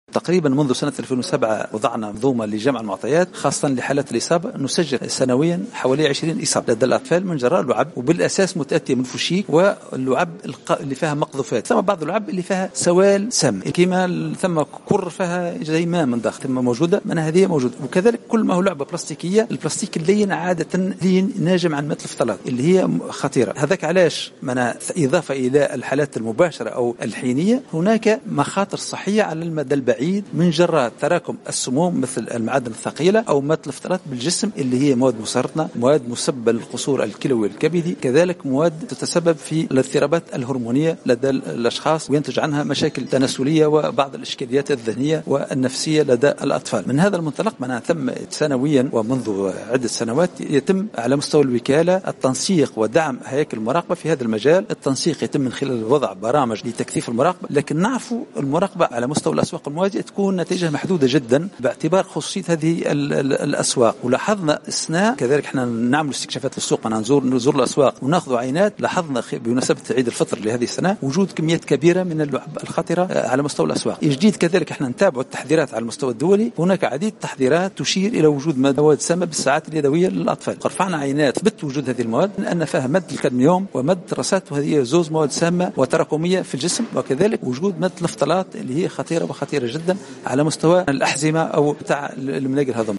أكد مدير عام الوكالة الوطنية للرقابة الصحية والبيئية للمنتجات مبروك النظيف في تصريح
خلال ندوة صحفية عقدتها وزارة الصحة